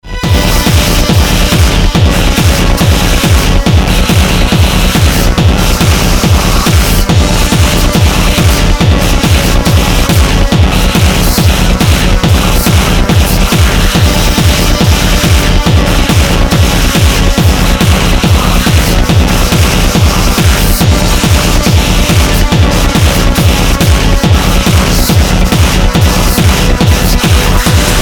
Это тестовый трек (фрагмент). На кубах норм звучит.
На 100-х совсем кровь из ушей!
На crashes внимание не обращайте, их надо вывести из под сатурации и будет норм.